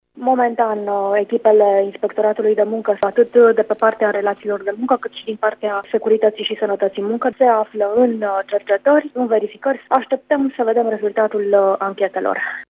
La această oră, echipele Inspectoratului fac controale pe partea de relații și securitate în muncă, a precizat pentru RTM directorul ITM Mureș, Eva Man: